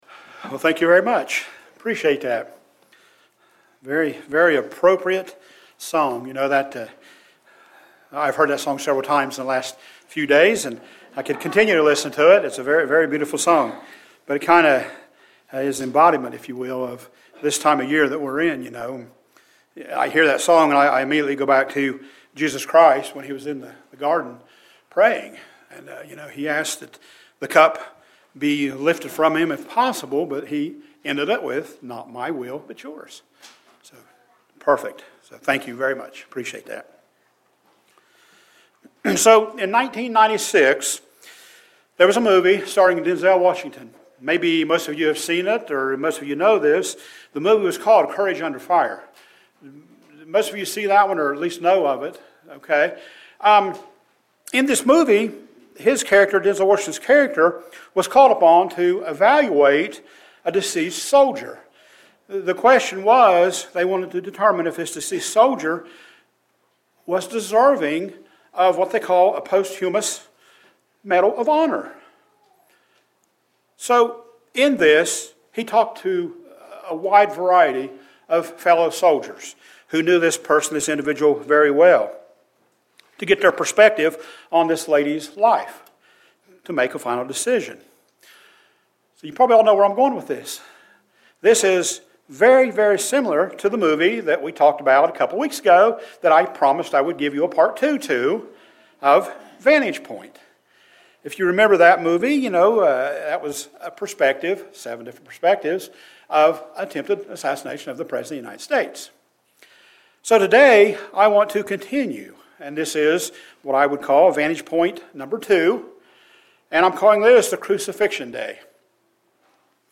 This movie was similar to the movie "Vantage Point" that we talked about earlier in a sermon. Today we will continue with the second half of the sermon.